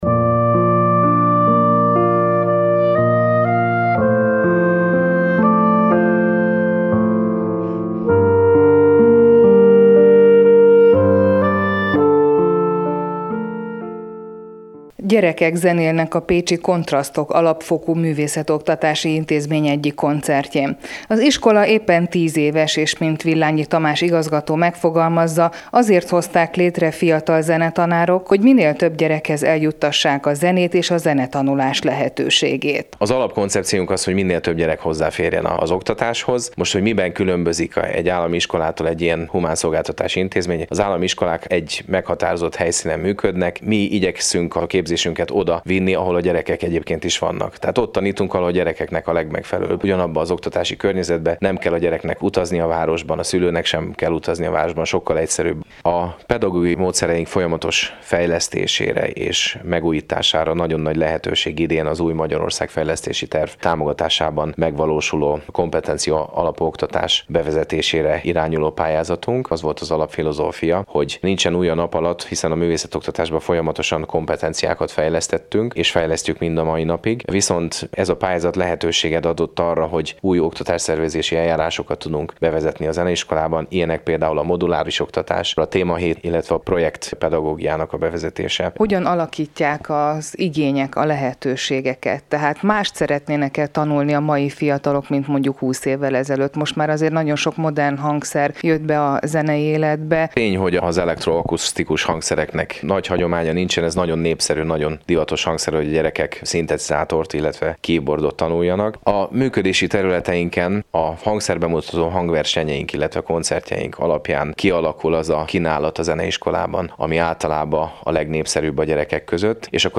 Rádióinterjú a 101,7 MHz-en